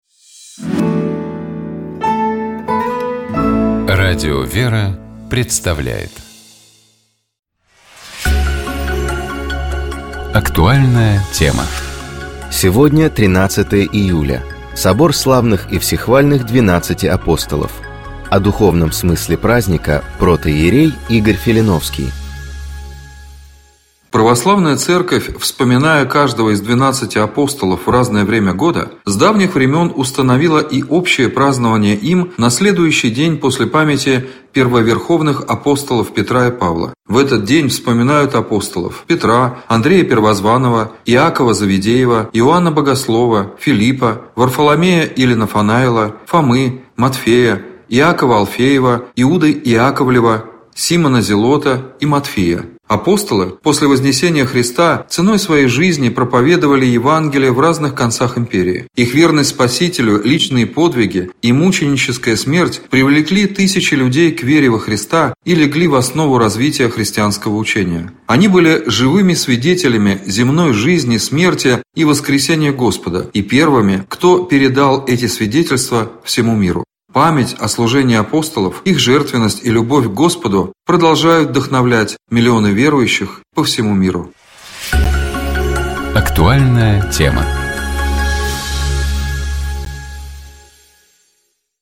В нашей студии был клирик